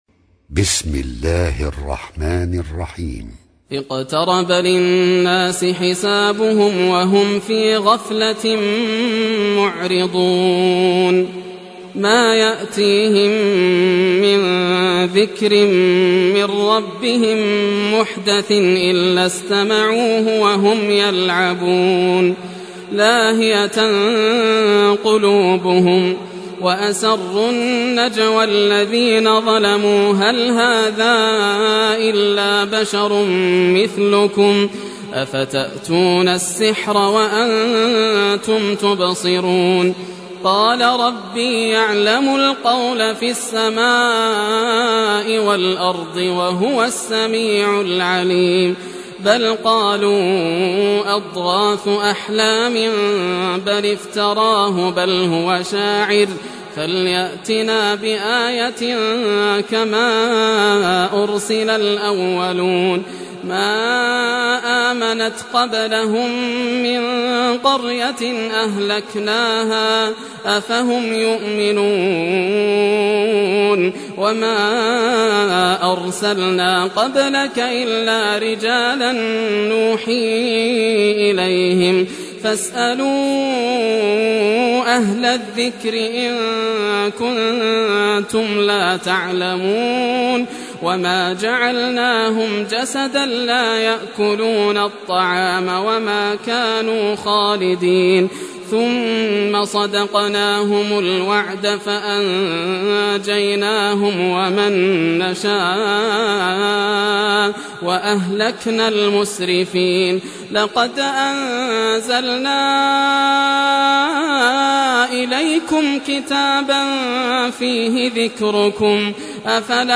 Surah Al-Anbya Recitation by Sheikh Yasser Dosari
Surah Al-Anbya, listen or play online mp3 tilawat / recitation in Arabic in the voice of Sheikh Yasser al Dosari.